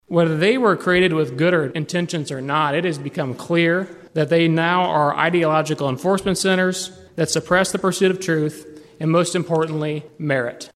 That’s Des Moines County Republican Representative Taylor Collins.